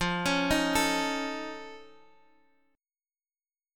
F+7 chord